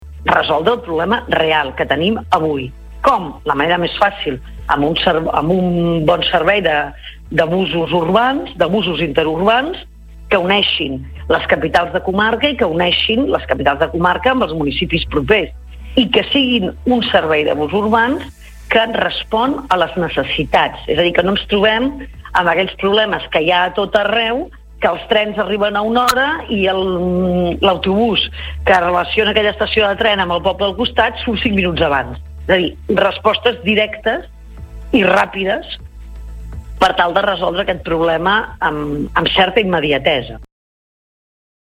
Entrevistes SupermatíNotícies